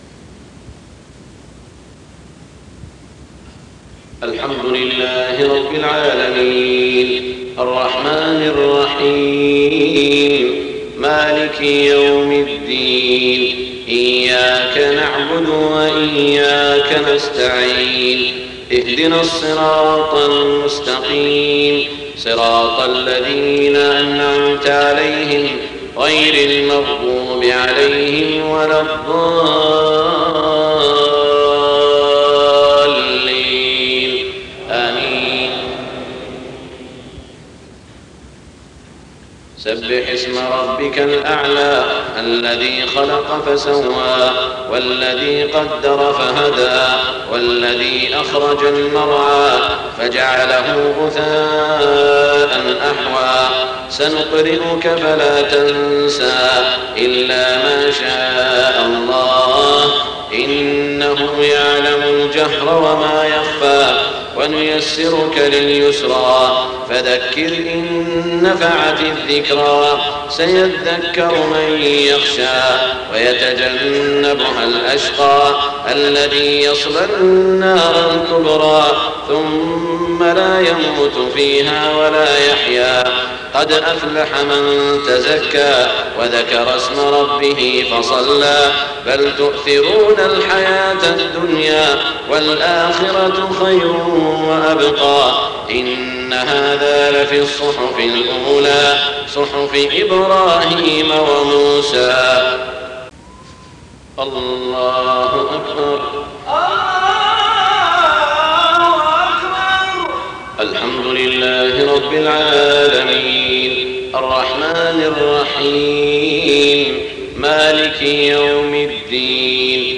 صلاة الجمعة 17 ذو القعدة 1427هـ سورتي الأعلى و الغاشية > 1427 🕋 > الفروض - تلاوات الحرمين